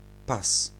Ääntäminen
France (Paris): IPA: [la taj]